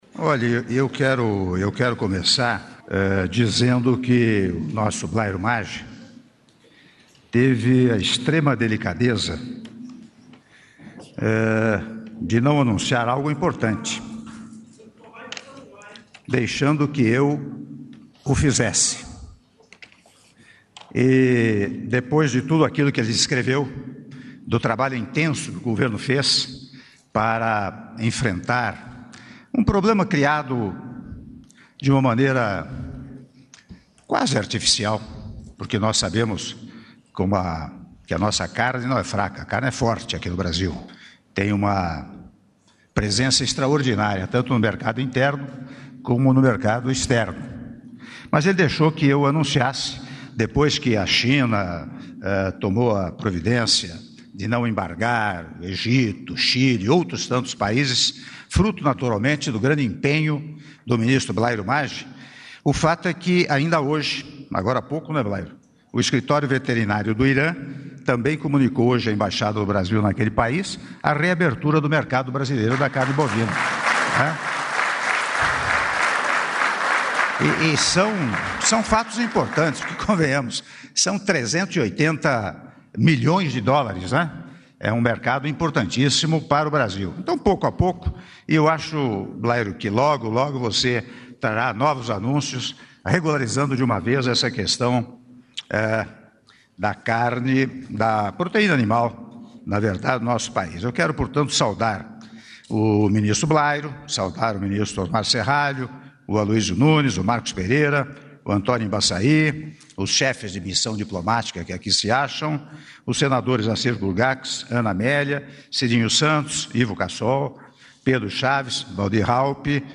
Áudio do discurso do Presidente da República, Michel Temer, durante cerimônia de Assinatura do Decreto que dispõe sobre o Regulamento da Inspeção Industrial e Sanitária de Produtos de Origem Animal (RIISPOA) - Brasília/DF (08min15s)